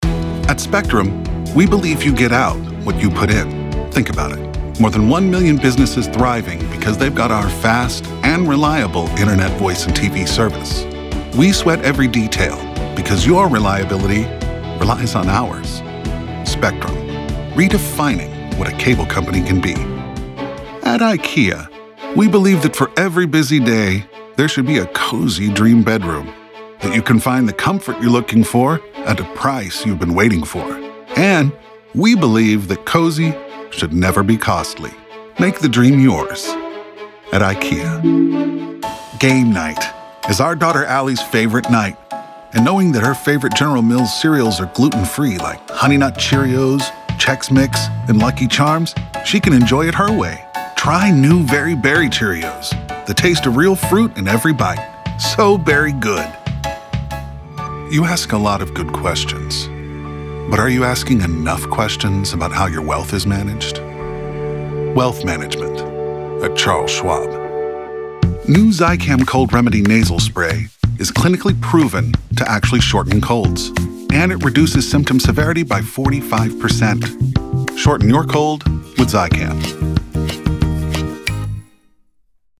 Demos
Commercial